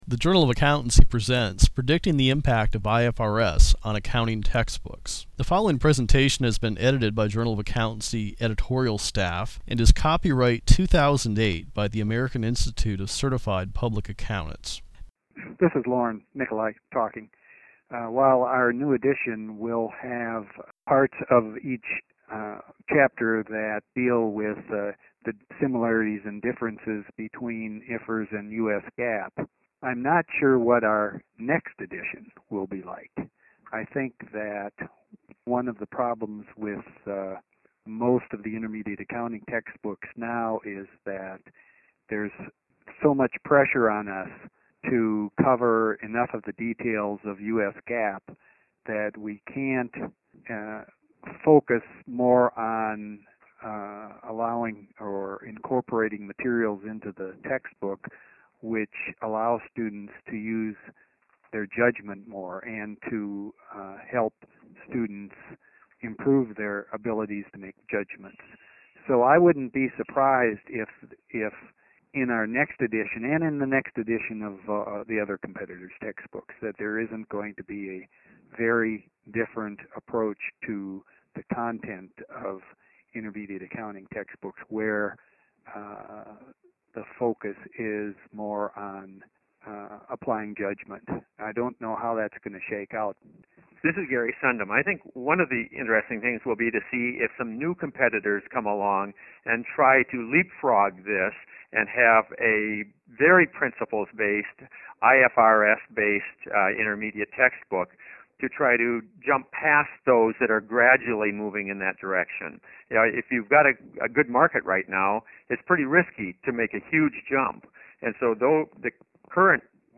To get a sense of the effect IFRS is having at colleges and universities, the JofA hosted a virtual round table, gathering eight accounting professors from around the country by conference call (for a detailed list of panelists, see below).
Here we present audio clips from the round-table discussion, which is detailed in the December issue of the JofA: